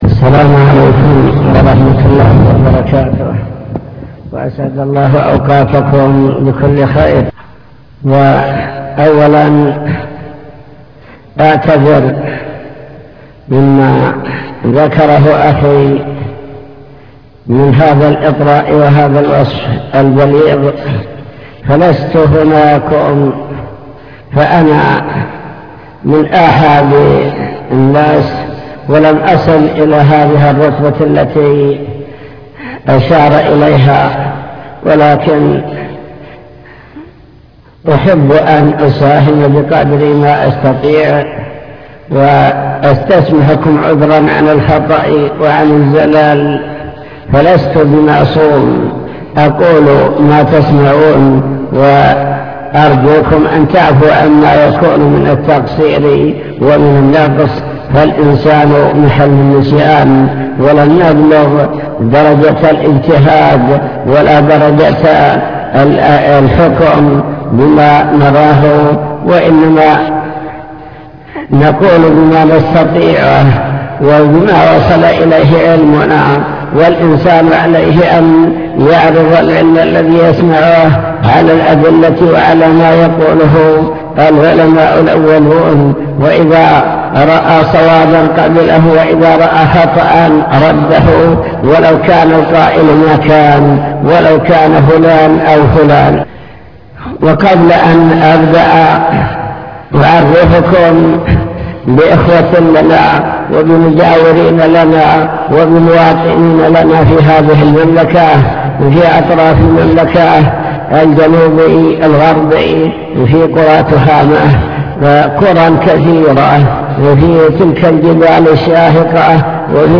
المكتبة الصوتية  تسجيلات - محاضرات ودروس  الافتراق والاختلاف الكلام عن الخلاف وأسبابه